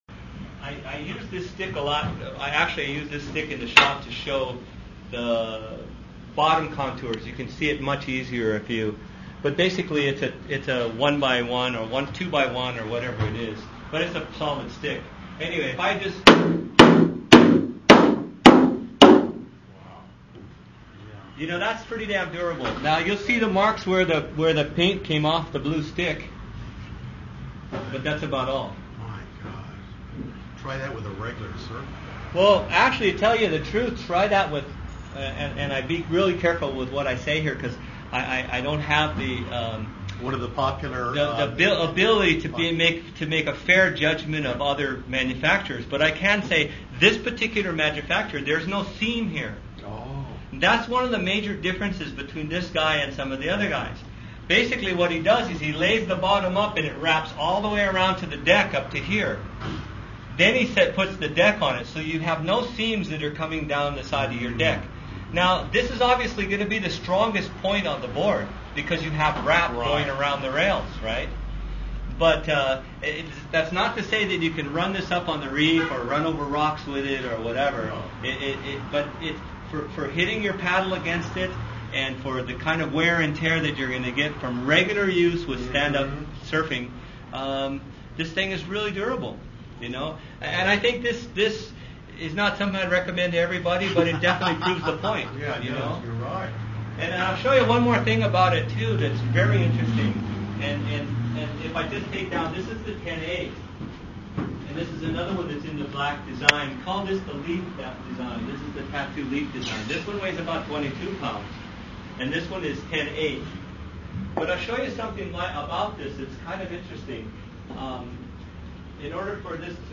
Even the customer tries a jump or 2.